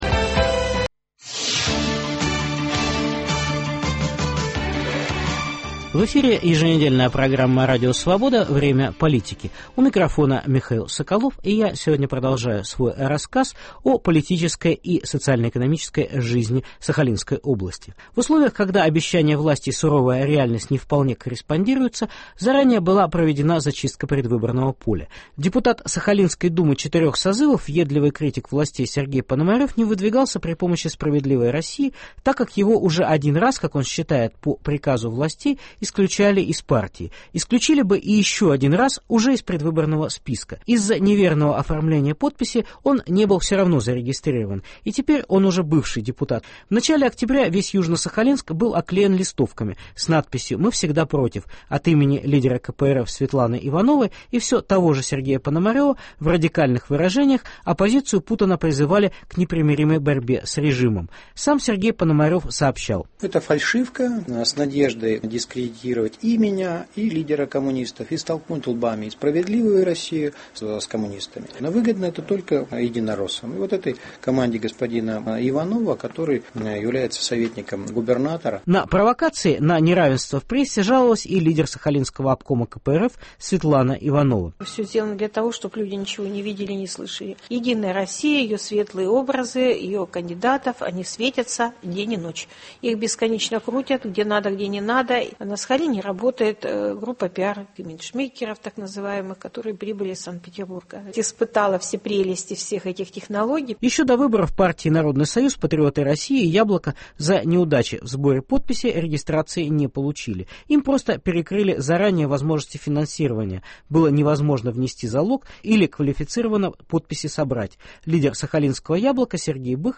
Специальный репортаж